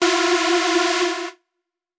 Index of /99Sounds Music Loops/Instrument Oneshots/Leads